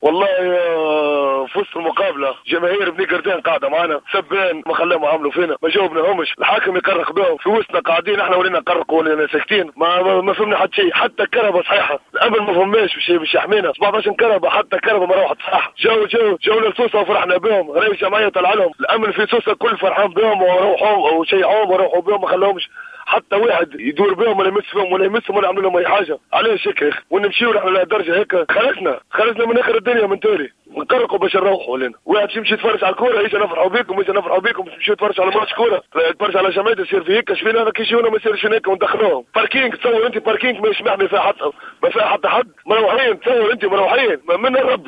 احد احباء النجم الساحلي